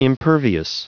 Prononciation du mot impervious en anglais (fichier audio)
Prononciation du mot : impervious